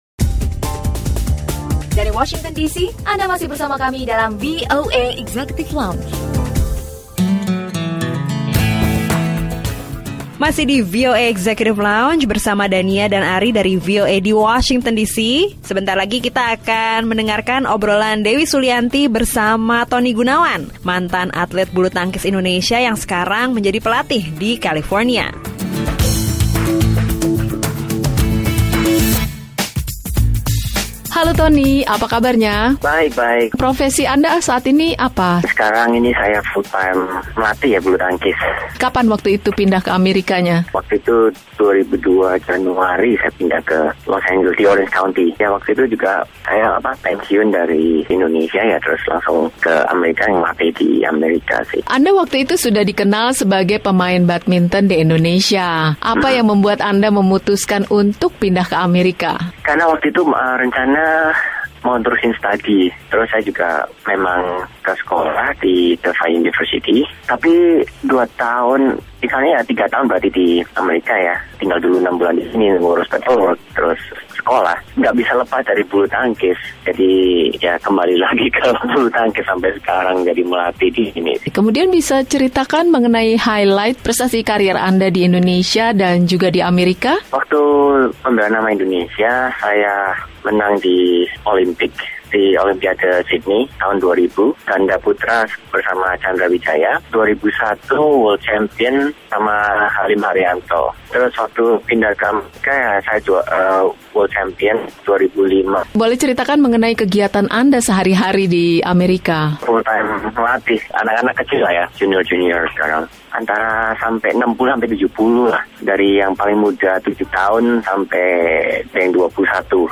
Obrolan bersama mantan pemain dan bintang bulu tangkis Indonesia, Tony Gunawan, yang kini menjadi pelatih bulu tangkis di Amerika Serikat.